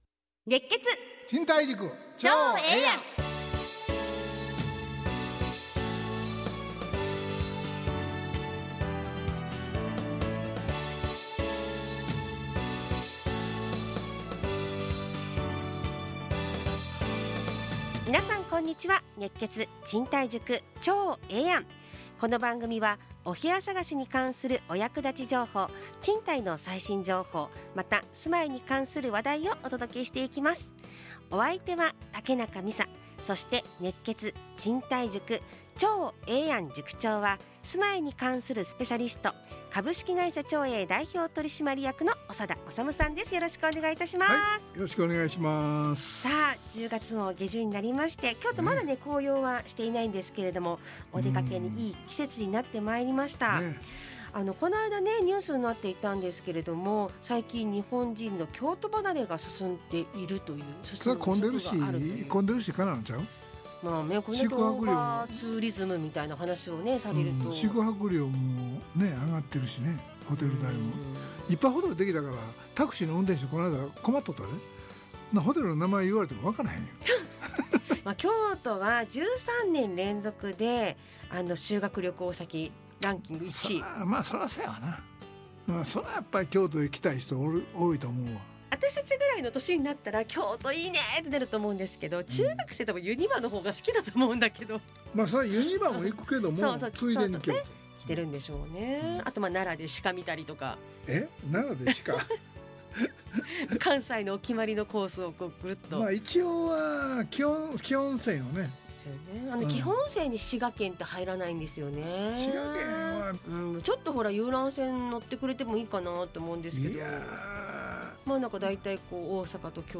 ラジオ放送 2025-10-27 熱血！